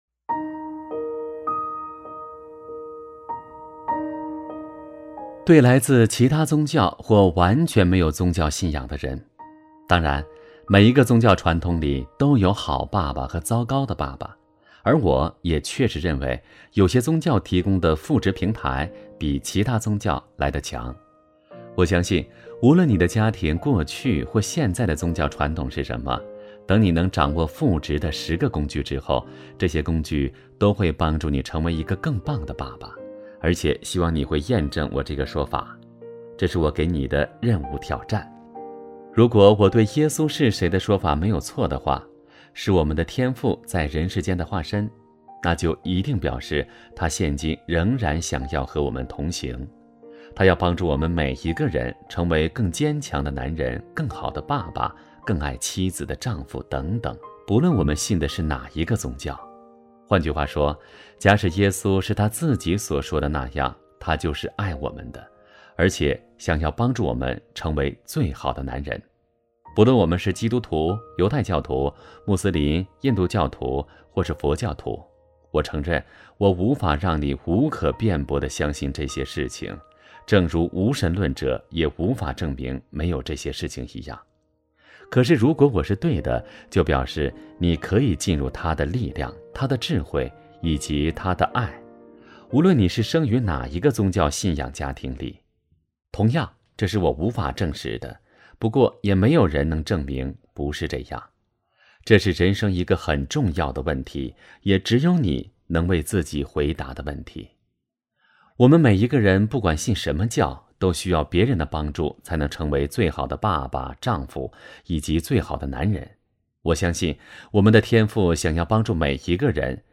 首页 > 有声书 > 婚姻家庭 | 成就好爸爸 | 有声书 > 成就好爸爸：30 对来自其他宗教或完全没有宗教信仰的人